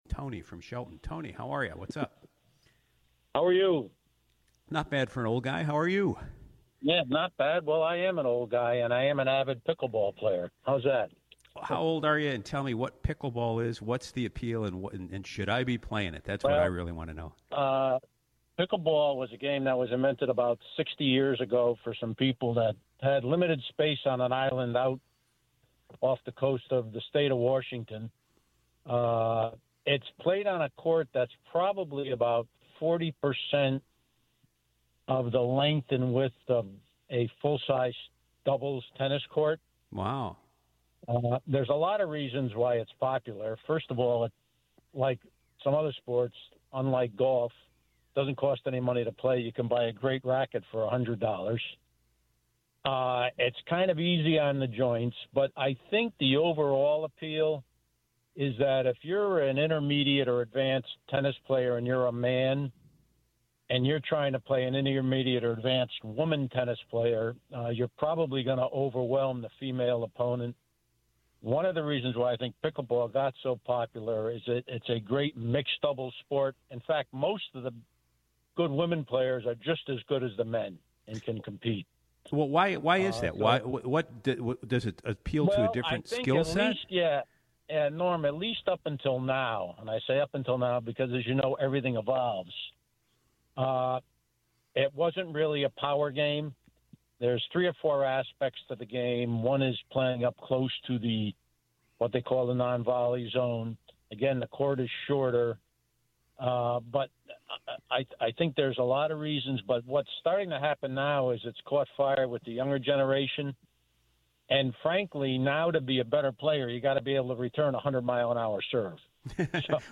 It was followed up with discussing one of Joe Rogan's recent topics, the homeless, where another caller called to give her thoughts on the homeless population after a trip to California (12:11).